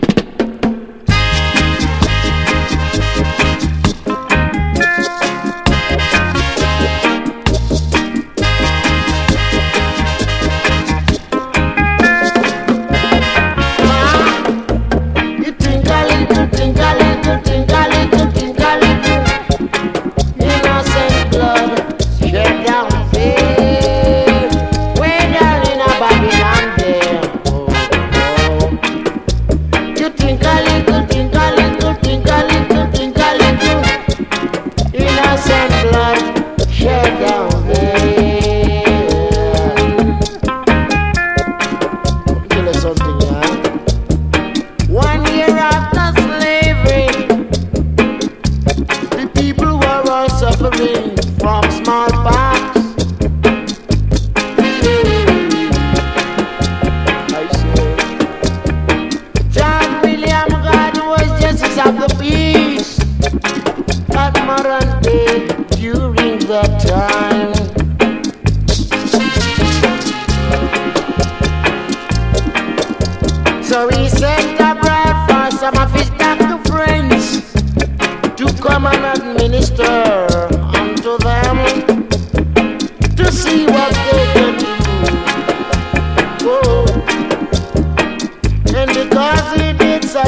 WORLD / REGGAE / SKA/ROCKSTEADY / NEO SKA / RHYTHM & BLUES